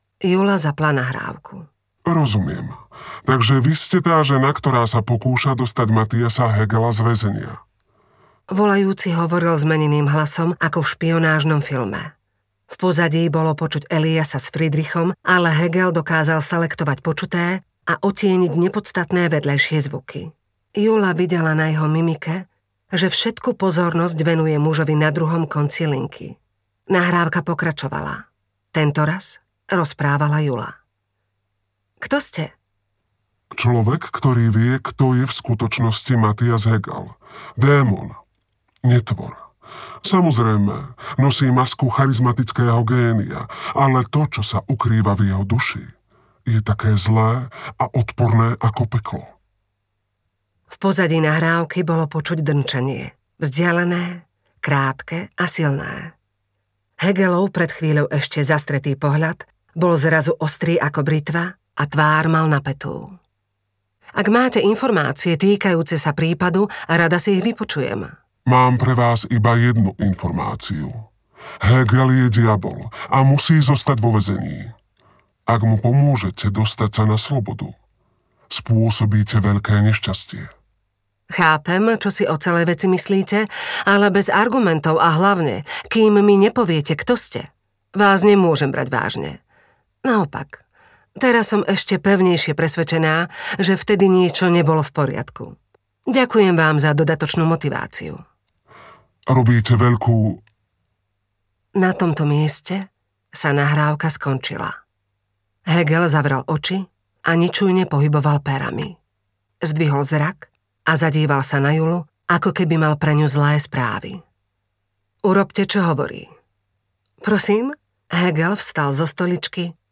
Auris audiokniha
Ukázka z knihy